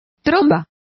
Complete with pronunciation of the translation of inrush.